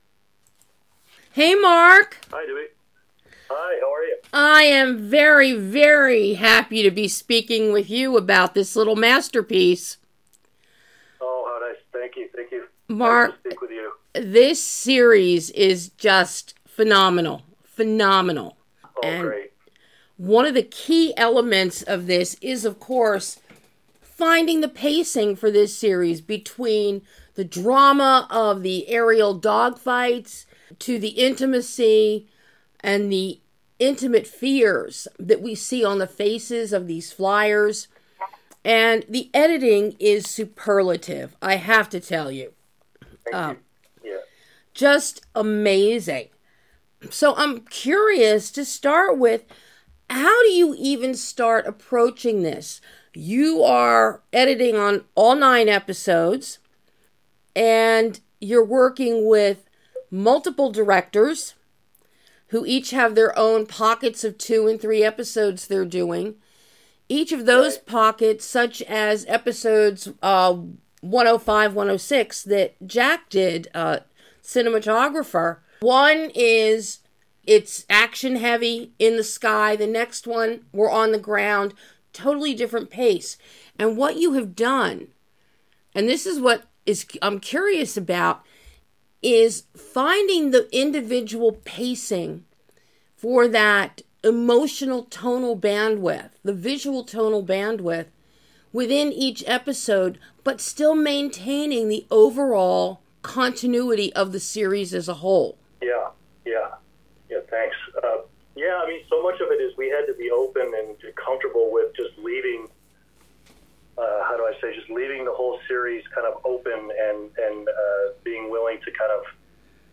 MASTERS OF THE AIR - Exclusive Interview